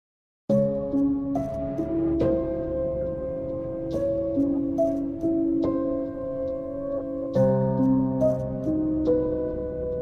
Spooky Tiktok Music Sound Button - Free Download & Play